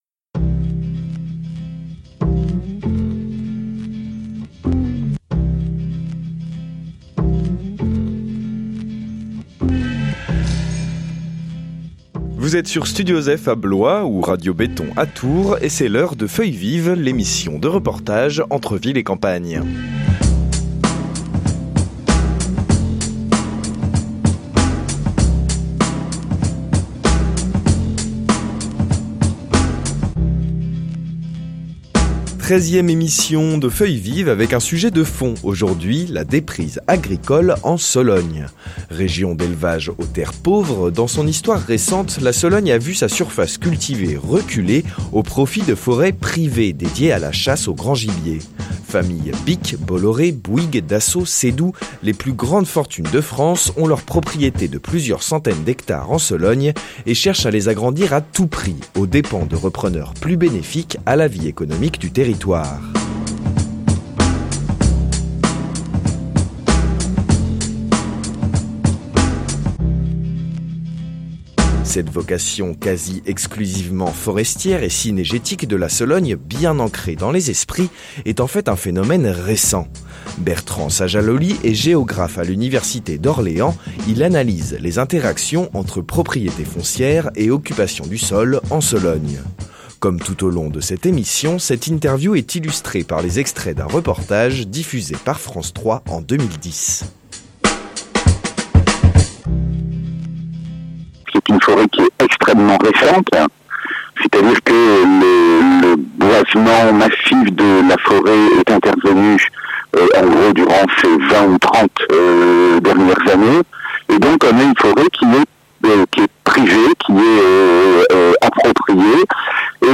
Pour cette treizième émission, Feuilles vives prend la direction de la Sologne avec un reportage sur la déprise agricole. Depuis les années 1950, et surtout depuis une trentaine d’années, les terres cultivées de Sologne reculent au profit de forêts inextricables (cf. cartes ci-dessous).